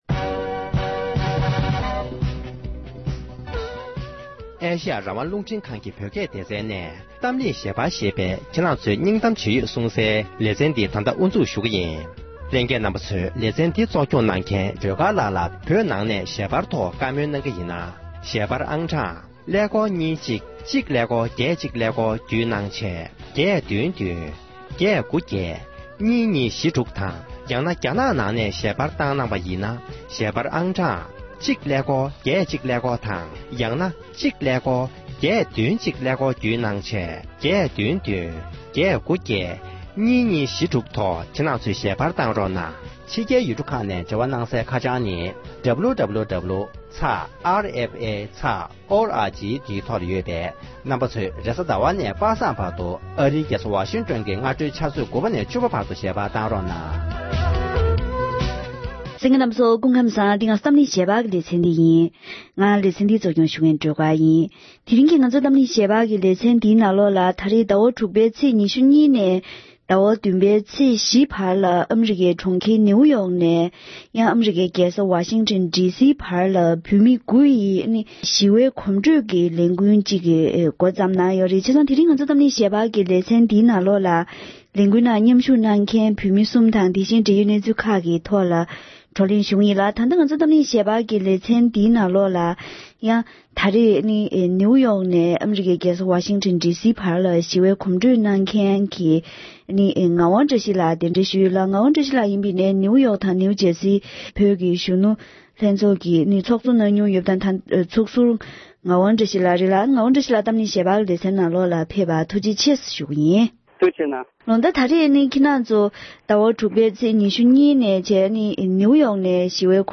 ཨ་རིའི་གྲོང་ཁྱེར་ནིའུ་ཡོག་ནས་རྒྱལ་ས་ཝ་ཤིང་ཊོན་བར་བོད་དོན་དྲིལ་བསྒྲགས་ཀྱི་ཞི་བའི་གོམ་བགྲོད་གནང་མཁན་མི་སྣ་ཁག་ཅིག་ལ་བཀའ་མོལ་ཞུས་པ་ཞིག་དང༌དེ་བཞིན་གོམ་བགྲོད་ལས་འགུལ་གྱི་ནུས་པའི་སྐོར།